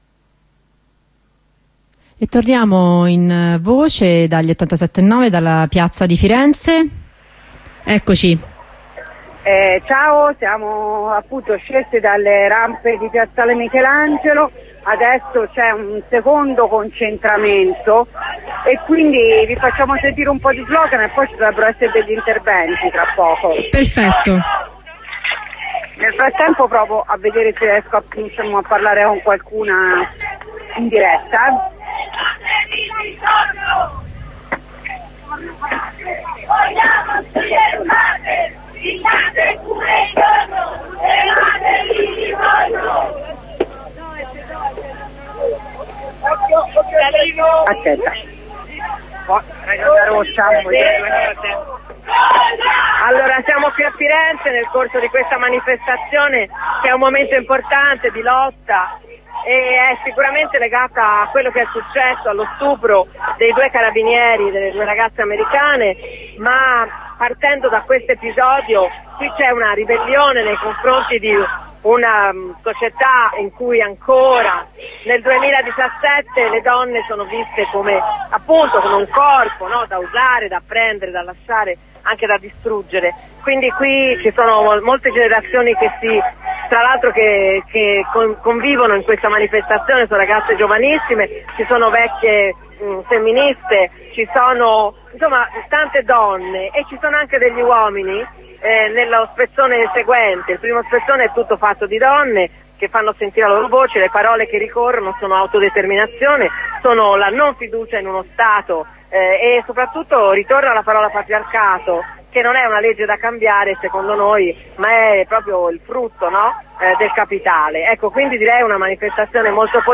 ror-corteo_Firenze_voci.ogg